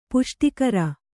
♪ puṣṭikara